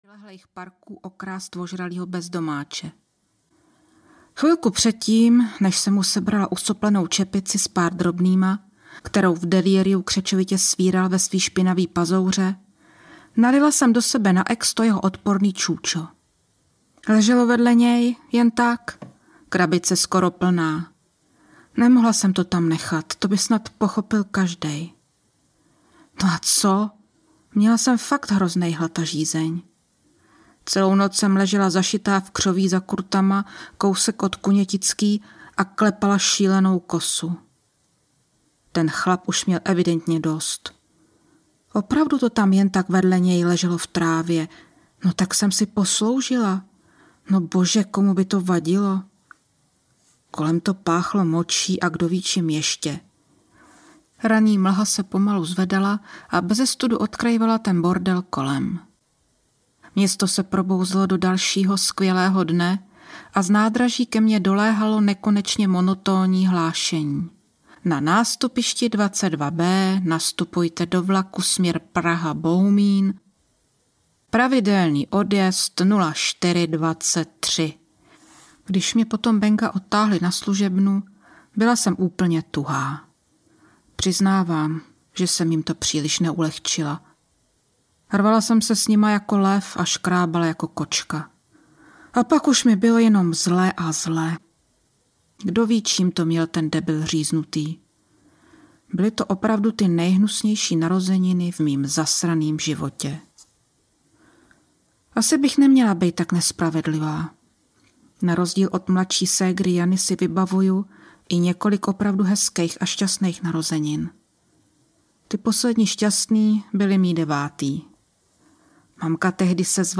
Jemčinská bestie audiokniha
Ukázka z knihy